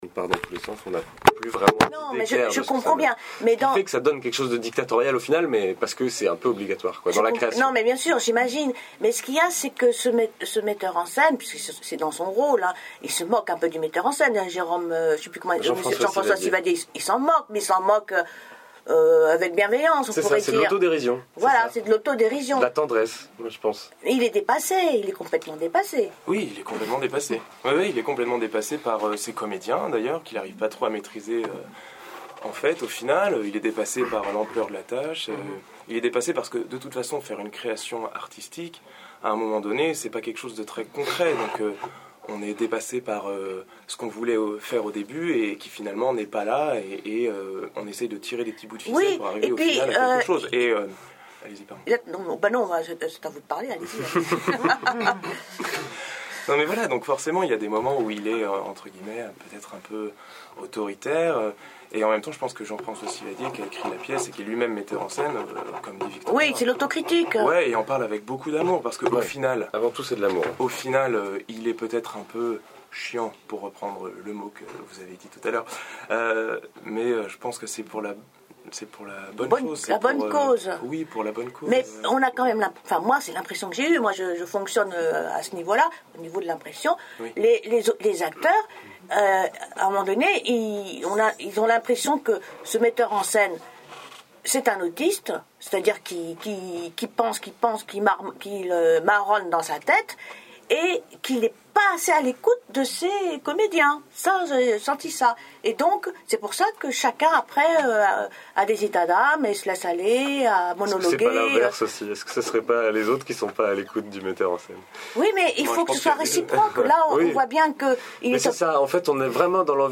EXTRAIT INTERVIEW ITALIENNE SCENE
a571a-extrait-interview-italienne-scene.mp3